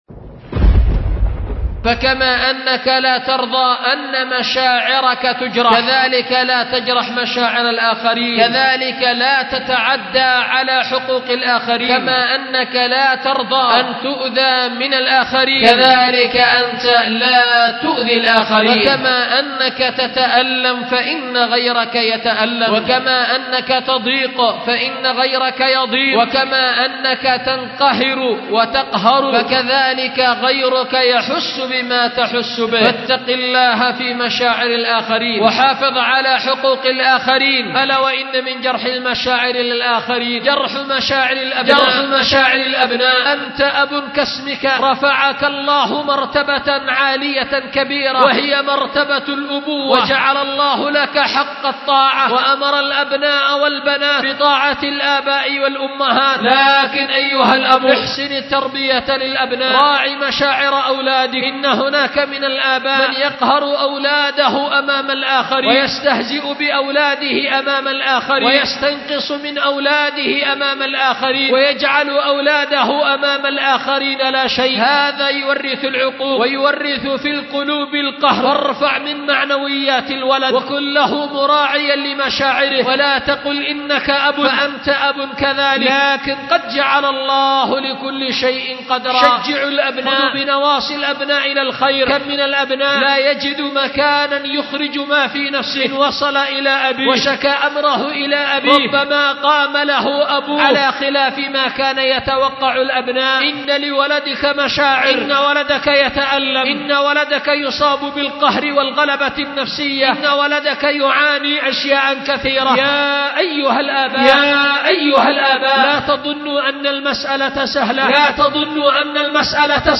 الخطبة الثانية : سرور الخاطر في أهمية مراعاة المشاعر
أُلقيت بدار الحديث للعلوم الشرعية بمسجد ذي النورين ـ اليمن ـ ذمار